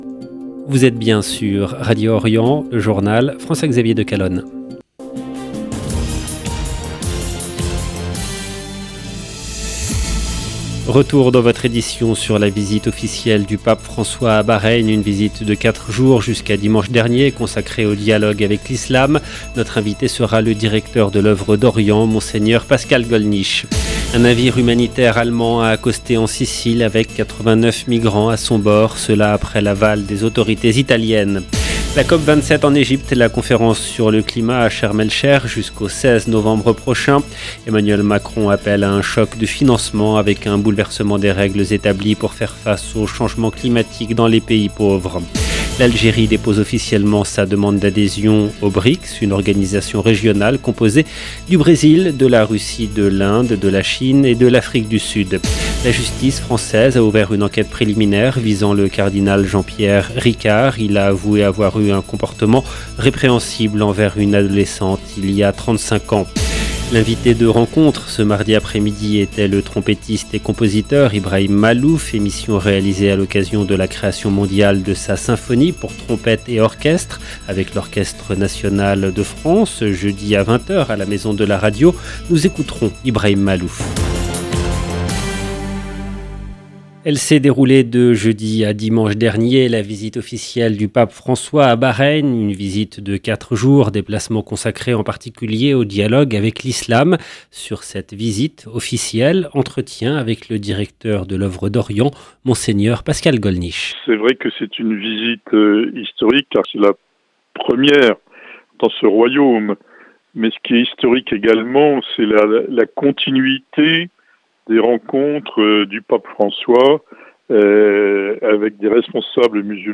LE JOURNAL DU SOIR EN LANGUE FRANCAISE DU 8/11/22